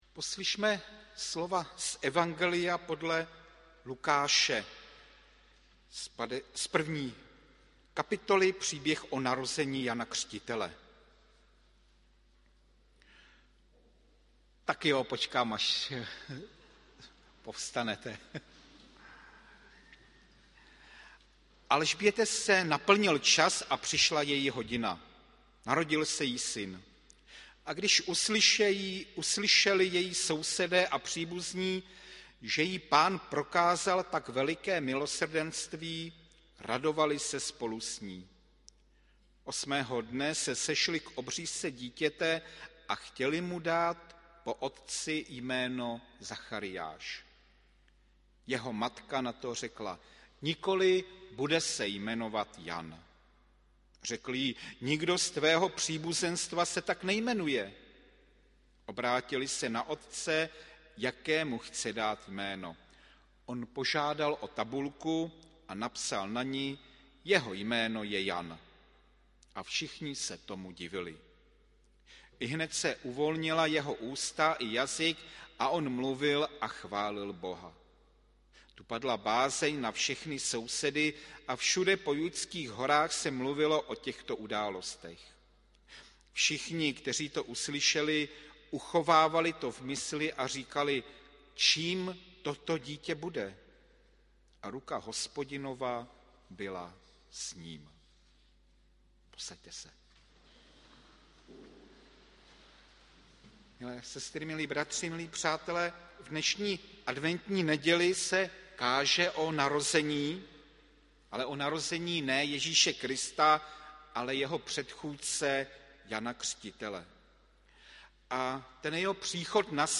Hudební nešpory 4. prosince 2022 AD
adventní hudba pro sbor, flétny a varhany v podání pěveckého sboru Resonance